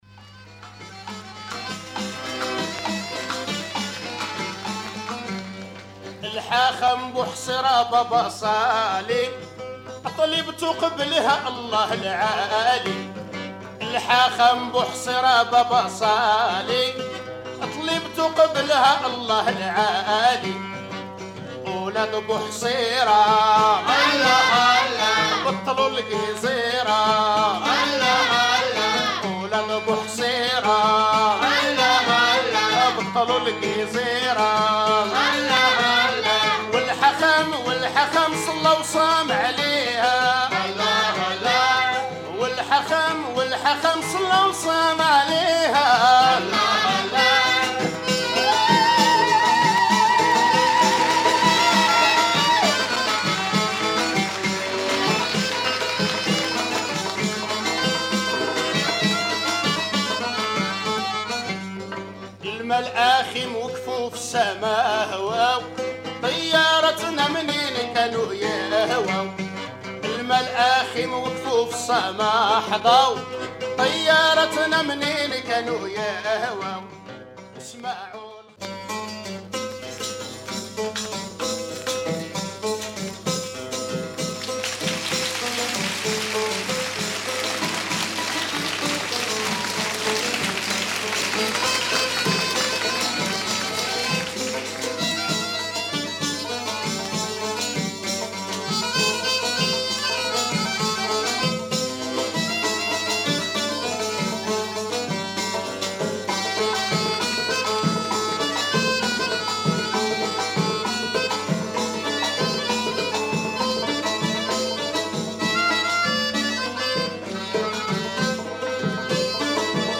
Moroccan song